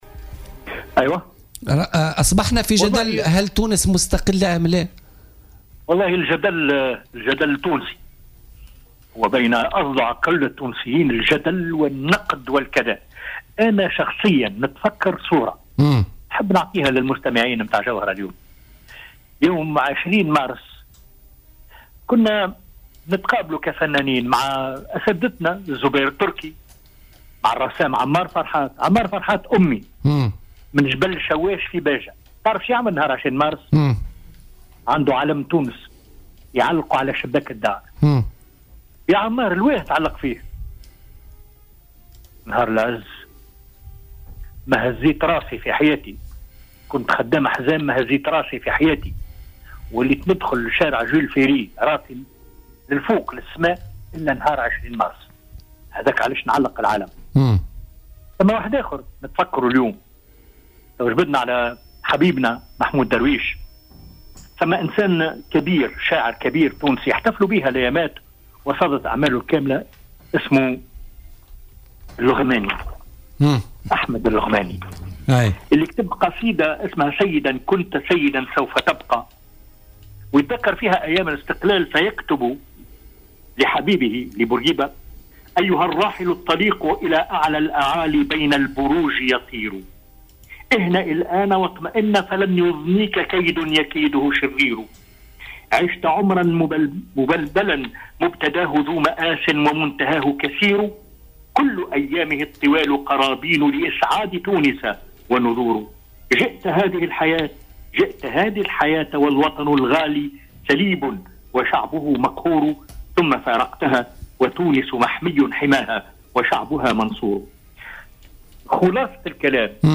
وذكّر في اتصال هاتفي ببرنامج "بوليتيكا" الذي خُصّص اليوم للحديث عن ذكرى عيد الاستقلال، بمواقف مهينة كان يتعرض إليها التونسي إبّان الاحتلال حيث كان يمشي ذليلا مطأطأ الراس في الأحياء الفرنسية في عقر بلده لأنه "عربي" قبل أن يرفع رأسه بكرامة بعد استقلال تونس.